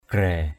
/ɡ͡ɣrɛ:/ (d.) quan thuế = douane.